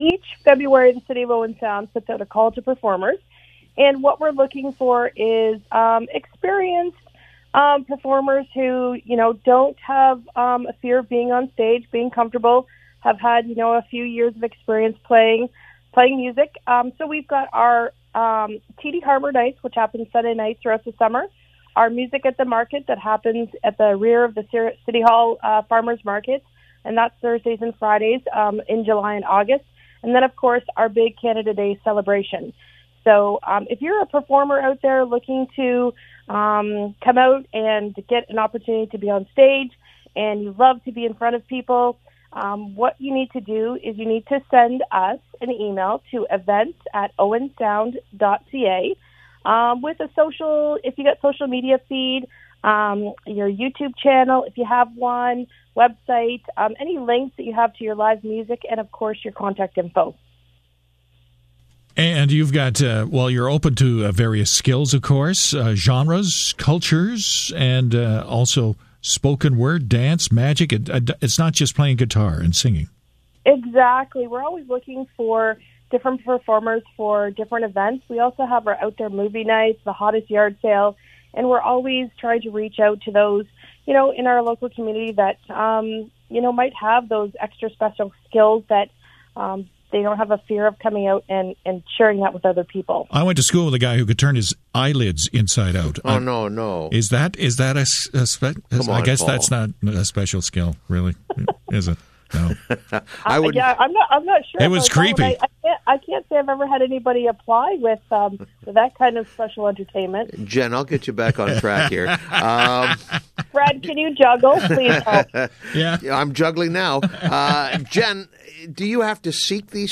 was a guest